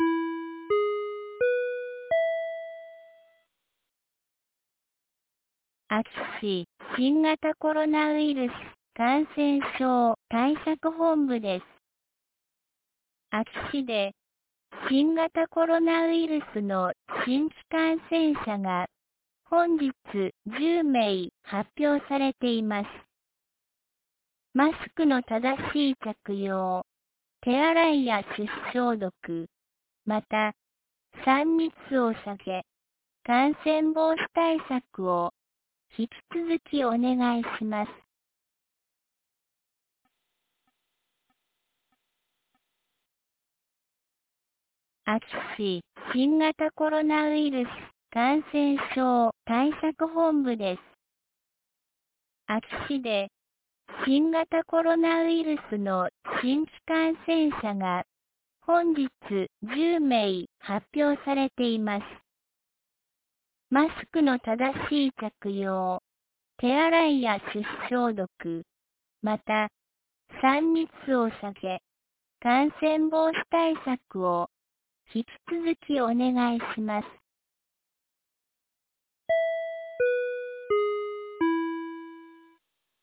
2022年08月08日 17時06分に、安芸市より全地区へ放送がありました。